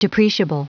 Prononciation du mot depreciable en anglais (fichier audio)
depreciable.wav